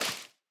sounds / step / wet_grass1.ogg
wet_grass1.ogg